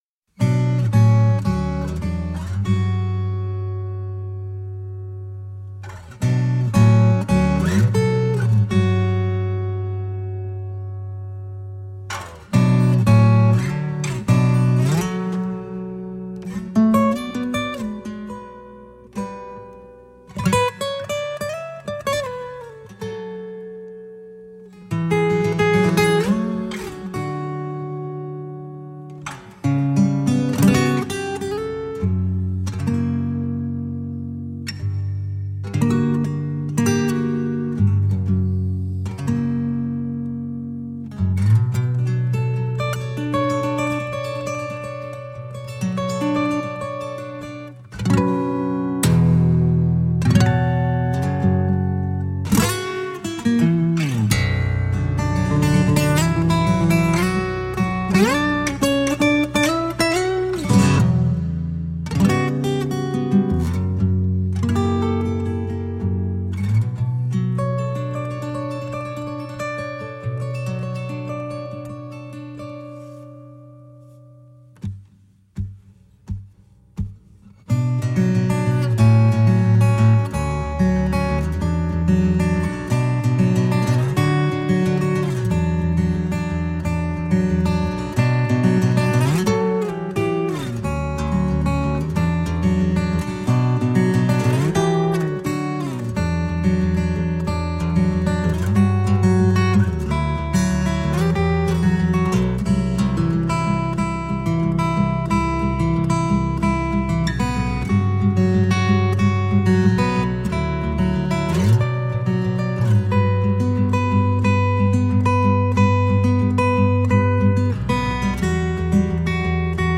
Acoustic percusive guitar.
Tagged as: Alt Rock, Guitar, Instrumental, Folk